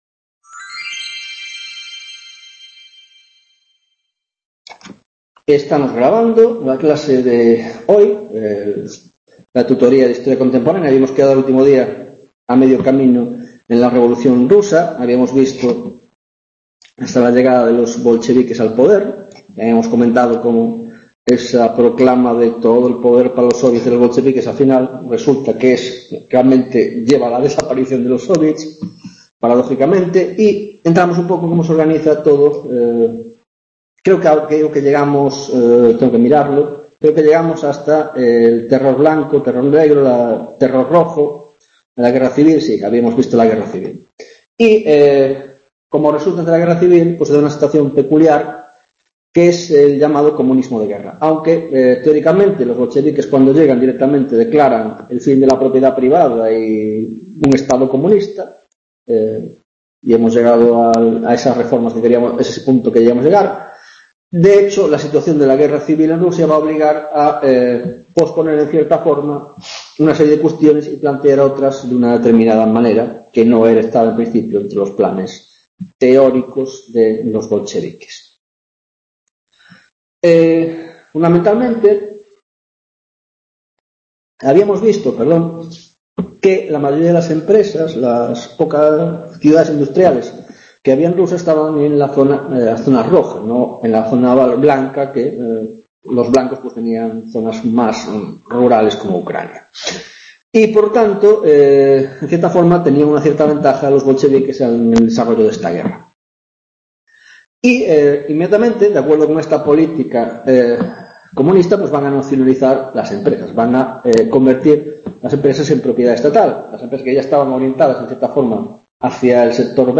16ª tutoria de Historia Contemporánea - Revolución Rusa 2ª Parte - Comunismo de Guerra, Nueva Política Económica y Dictadura de Stalin